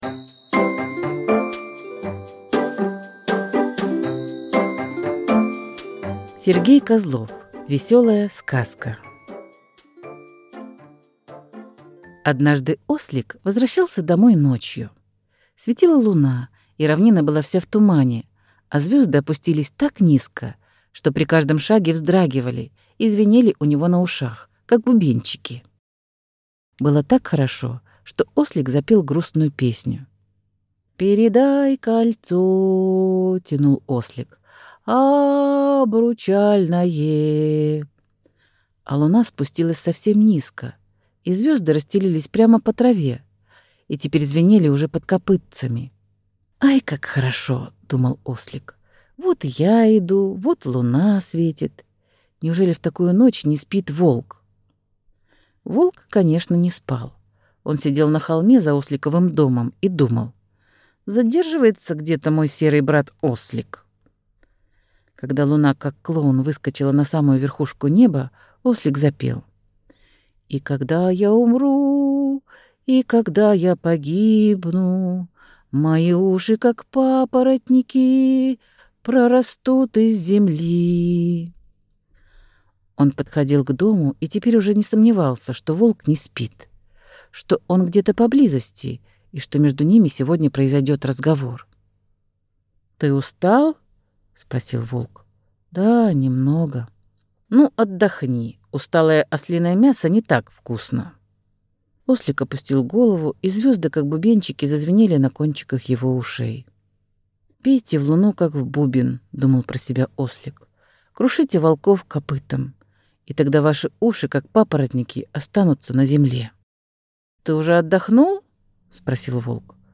Весёлая сказка - аудиосказка Сергея Козлова - слушать онлайн | Мишкины книжки